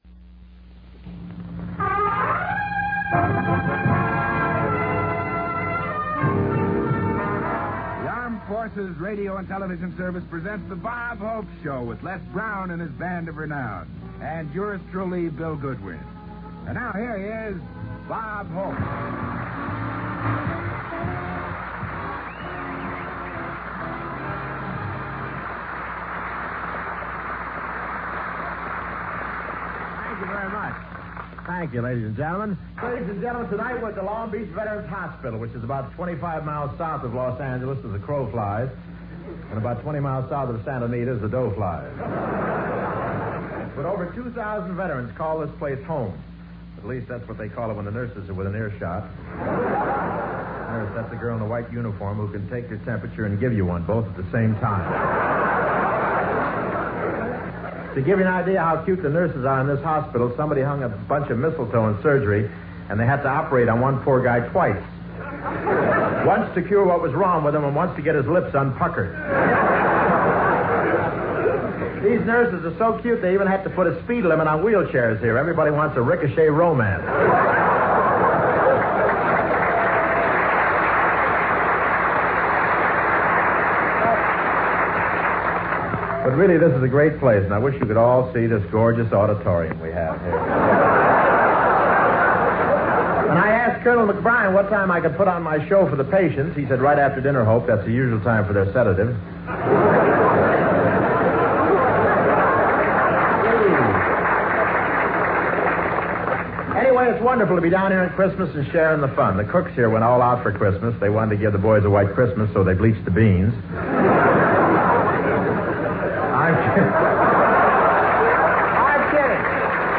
OTR Christmas Shows - From Long Beach Veteran\'s Hospital - Christmas - 1954-12-23 AFRS The Bob Hope Show